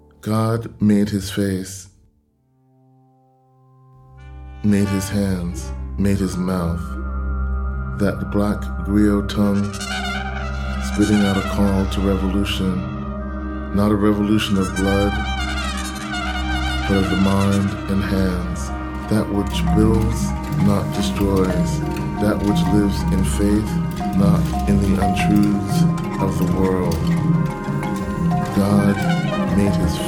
Basse (instrument)